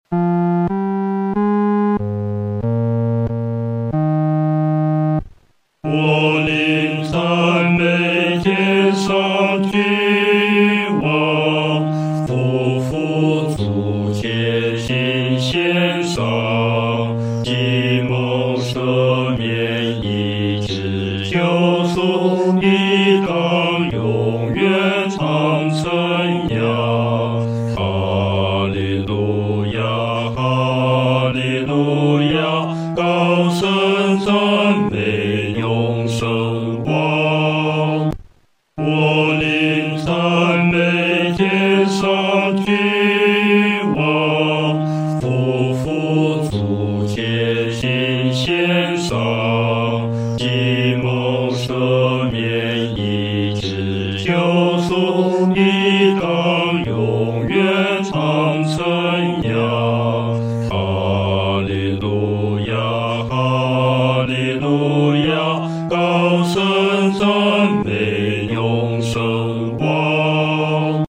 男低
本首圣诗由网上圣诗班 (南京）录制